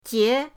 jie2.mp3